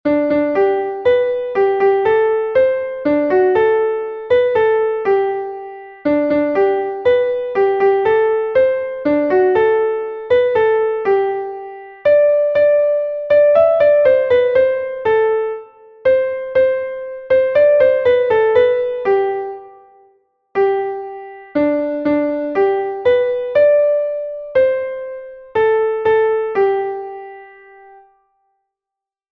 Einstimmige Melodie im Violinschlüssel, G-Dur, 3/4-Takt, mit der 1. Strophe des Liedtextes.
Melodie: flämische Volksweise (17.
hab-mein-wage-voll-gelade_klavier_melodiemeister.mp3